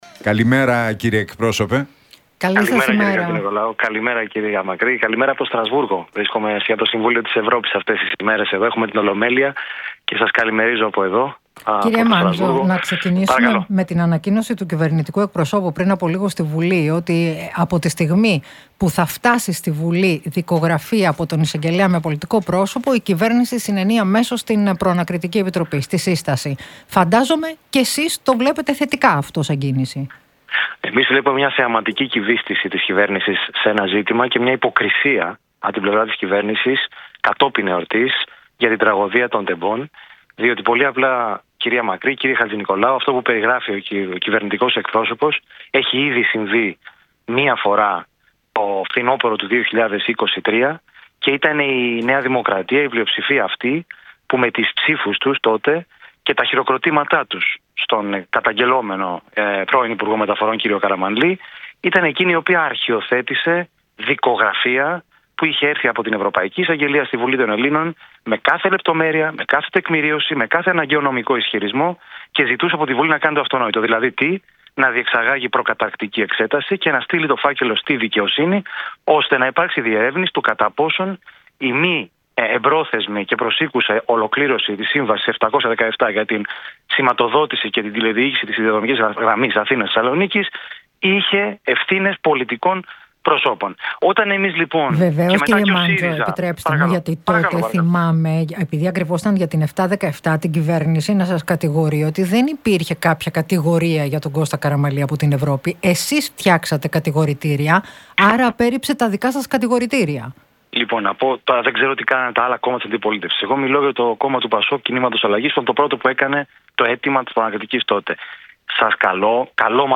Μάντζος στον Realfm 97,8: Θεαματική κυβίστηση και υποκρισία της κυβέρνησης για την τραγωδία των Τεμπών